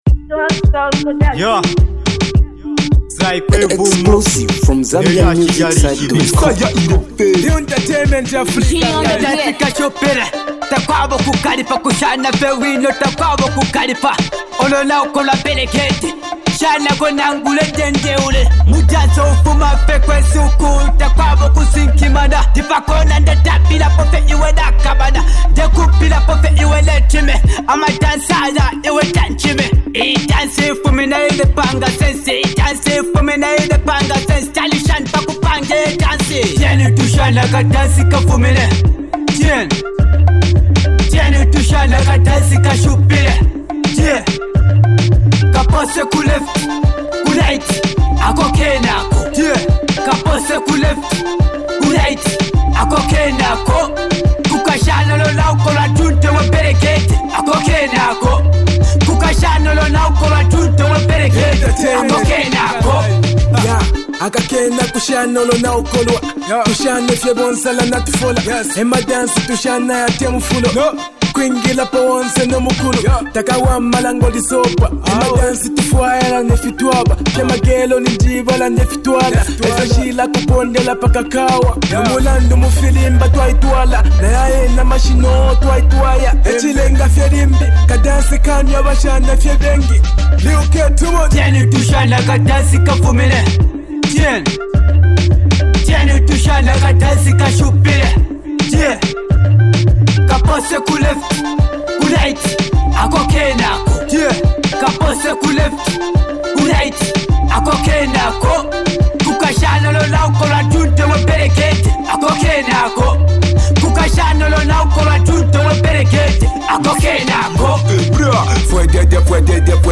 dancehall/club banger